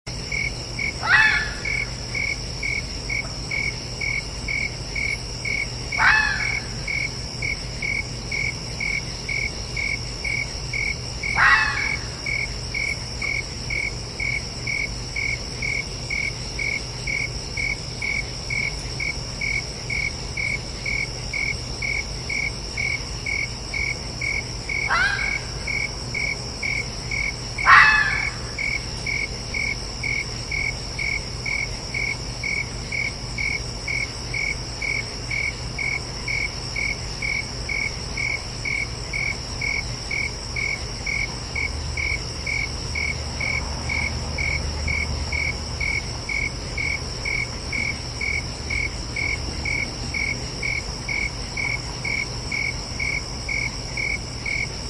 Sound Effects
Red Fox Calls At Night